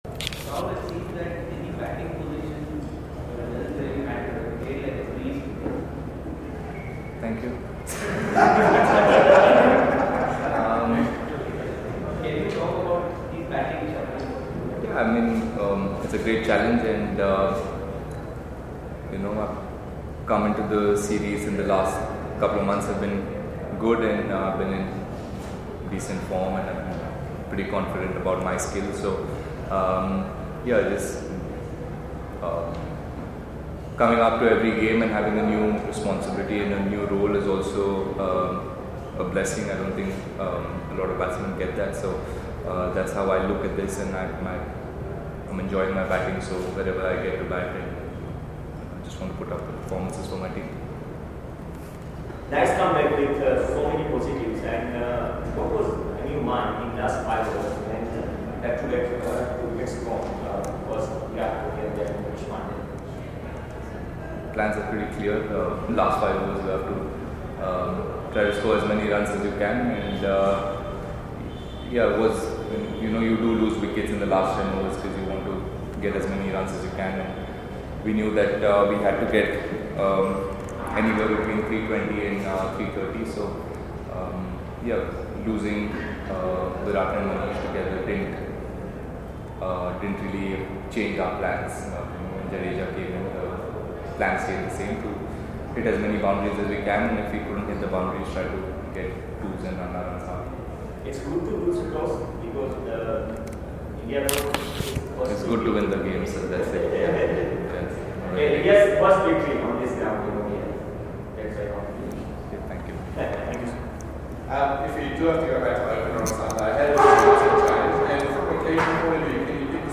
KL Rahul spoke to the media in Rajkot on Friday after the 2nd ODI against Australia.